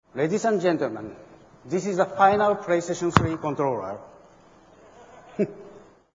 laughing at?